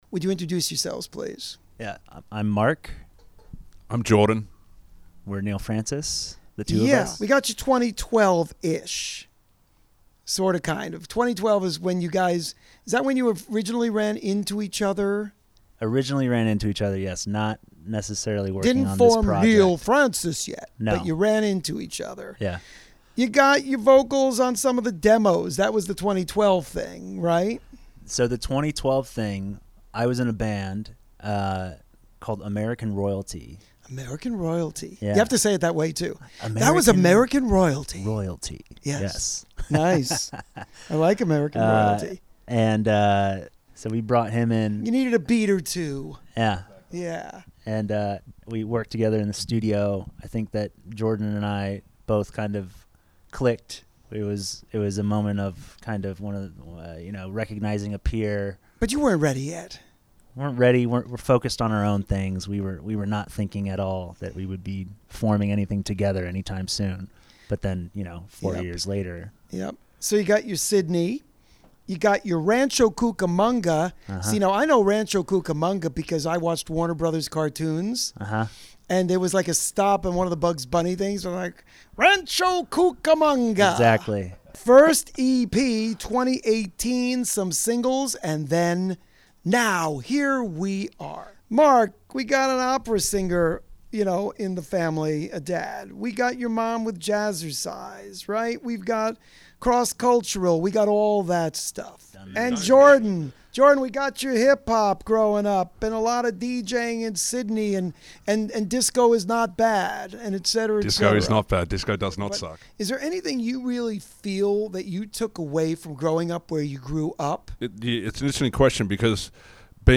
This Week's Interview (01/30/2022): Neil Frances